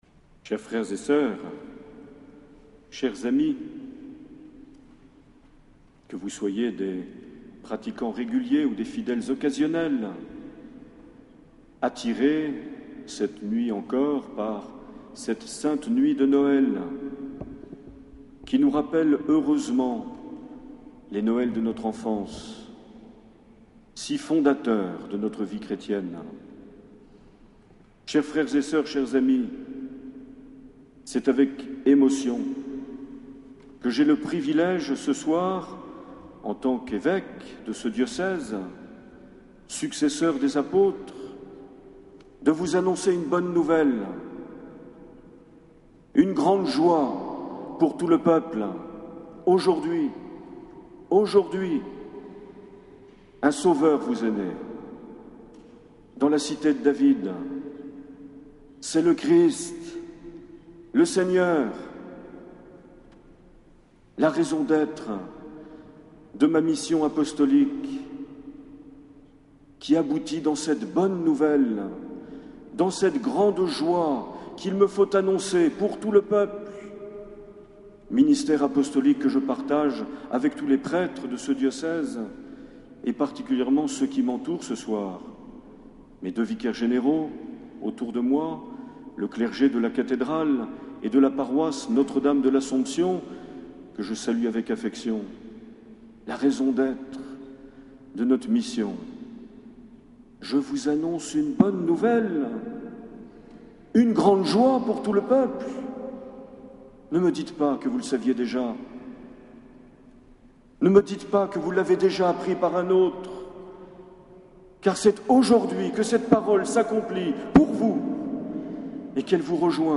25 décembre 2009 - Cathédrale de Bayonne - Nuit de Noël
Les Homélies
Une émission présentée par Monseigneur Marc Aillet